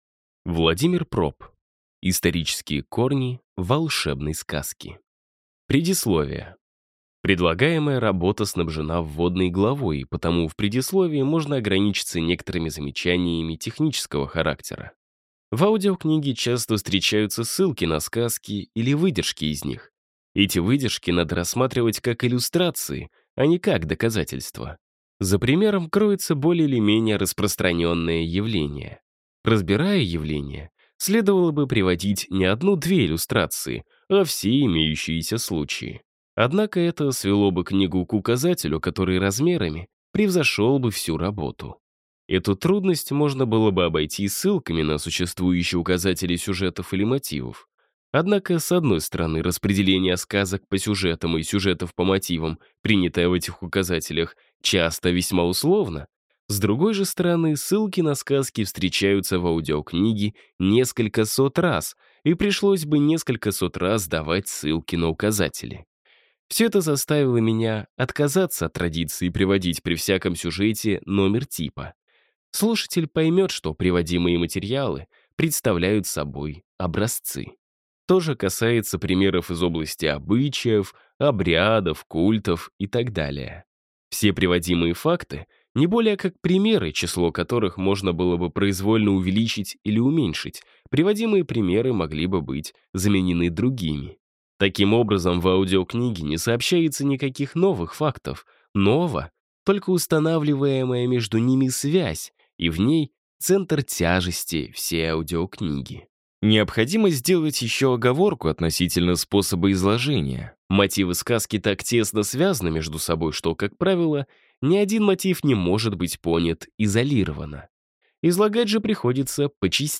Аудиокнига Исторические корни волшебной сказки. Часть 1 | Библиотека аудиокниг